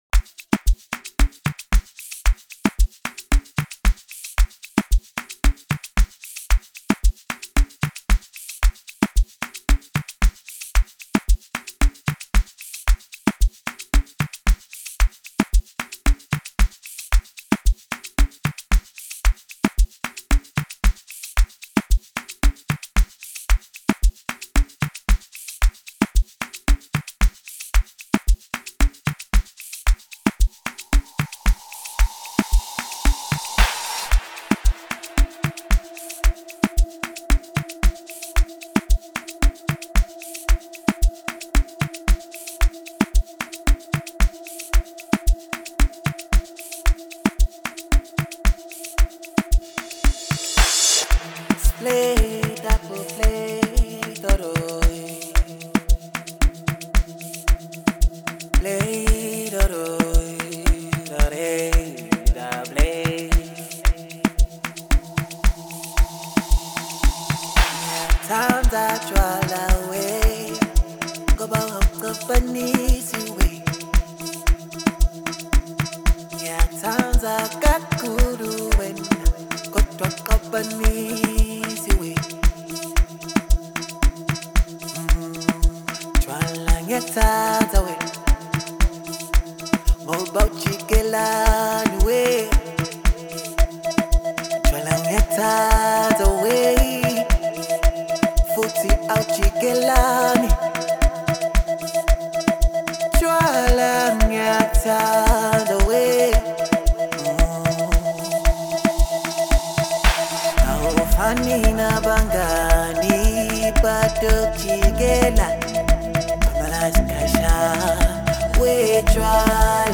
piano keys